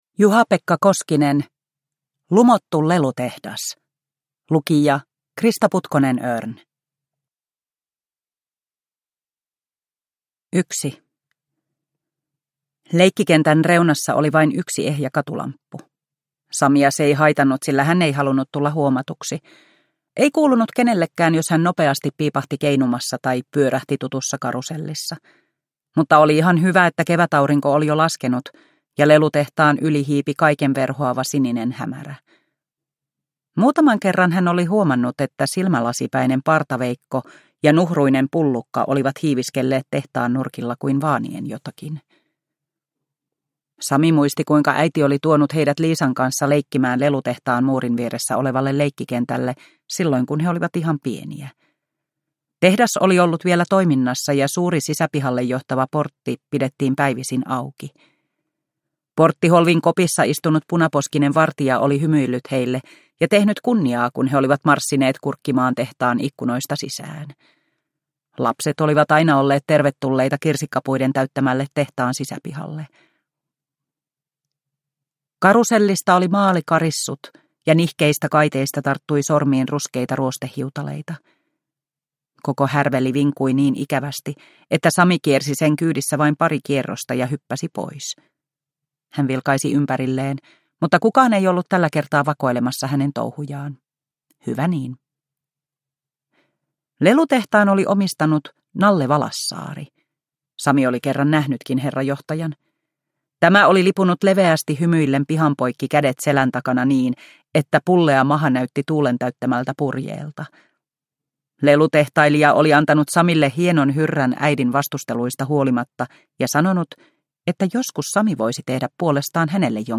Lumottu lelutehdas – Haavekaupunki 2 – Ljudbok